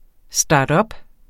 Udtale [ ˈsdɑːdʌb ]